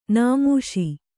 ♪ nāmūṣi